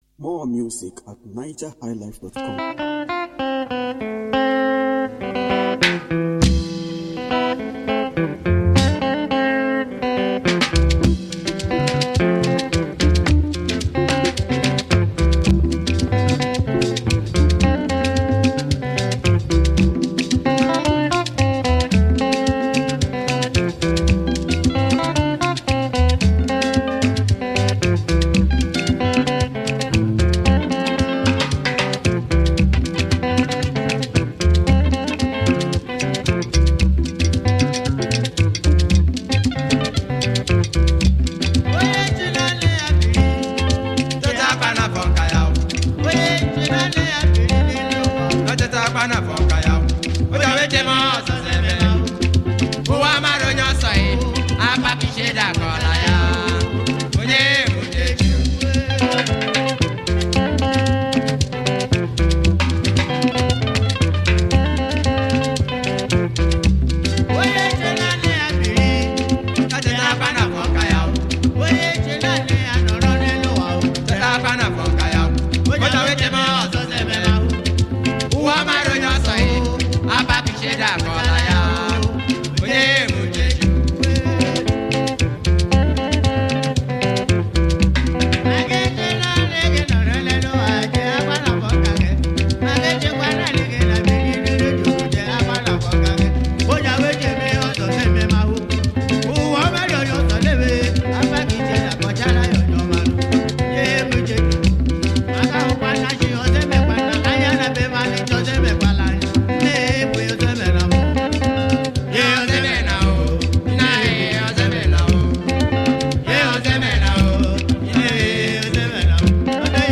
highlife band